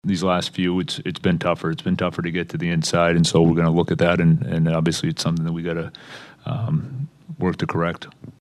Coach Dan Muse says the Pens continue to struggle to clog the ice in front of the opponents’ goaltender.